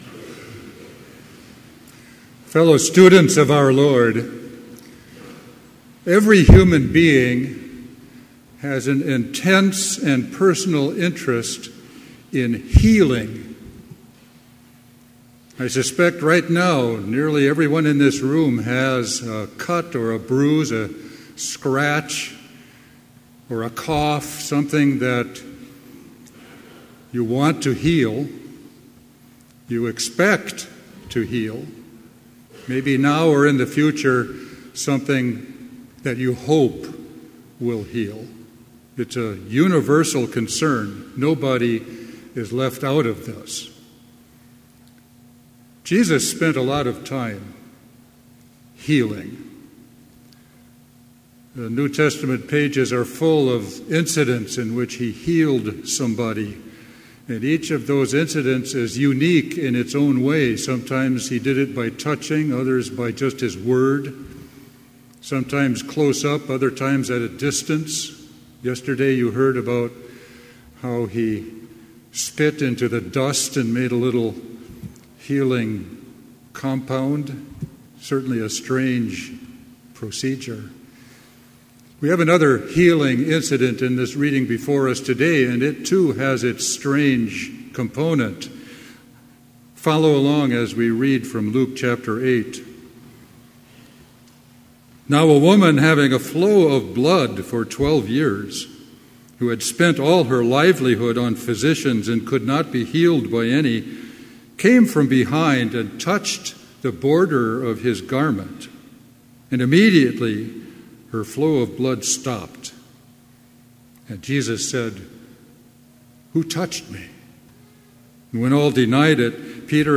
Complete service audio for Chapel - September 7, 2017